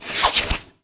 Wep Vacuum Suck Sound Effect
Download a high-quality wep vacuum suck sound effect.
wep-vacuum-suck.mp3